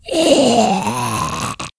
zombie-5.ogg